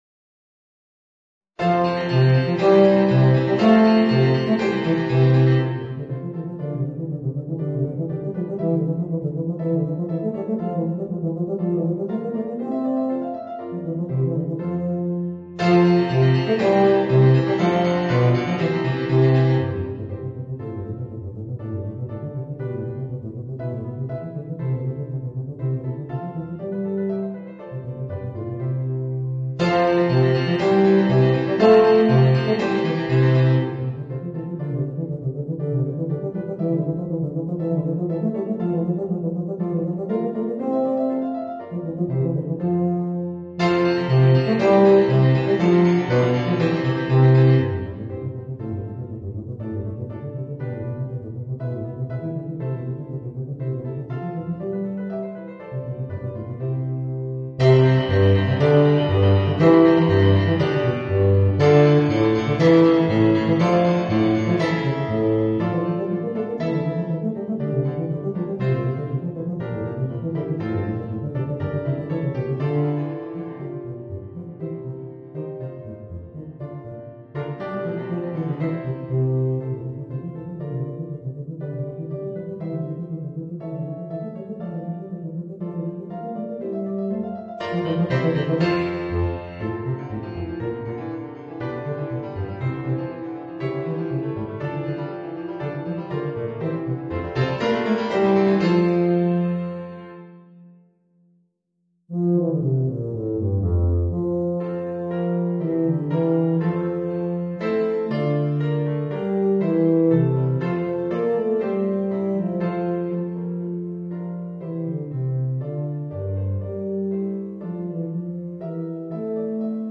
Voicing: Eb Bass and Organ